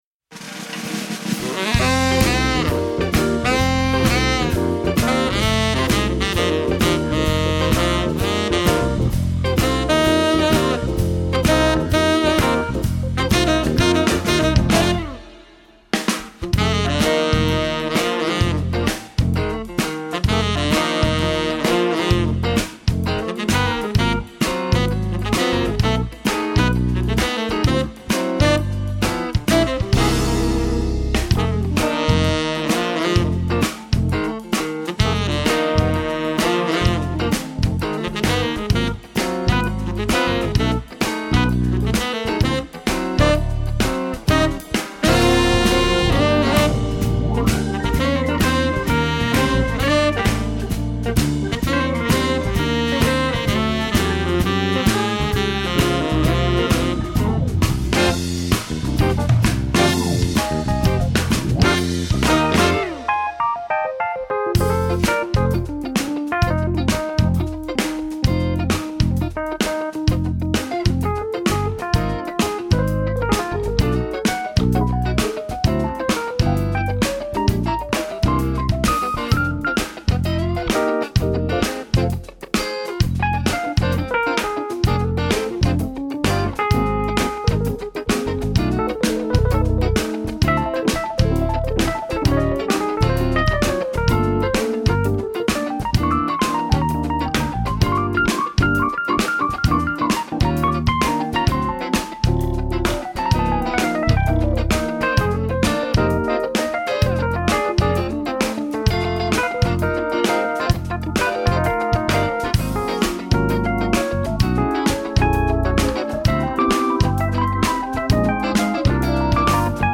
bobni
klavir
kitara